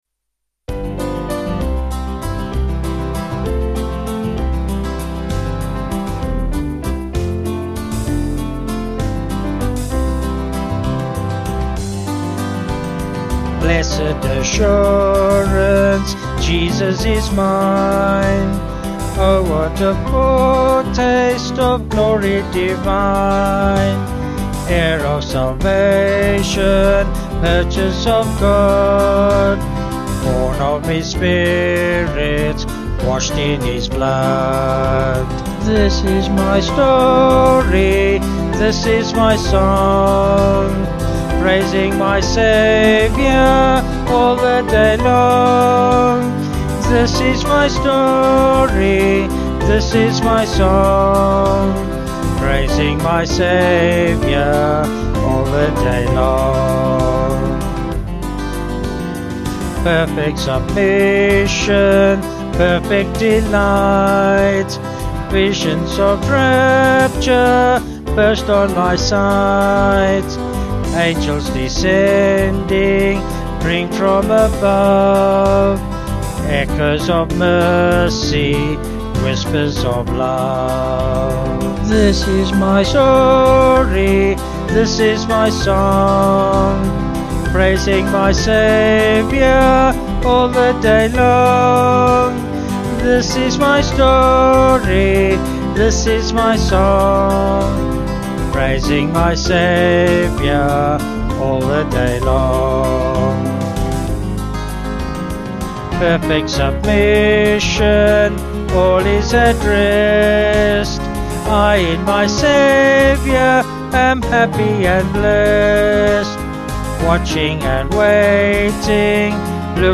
Vocals and Band   296.7kb Sung Lyrics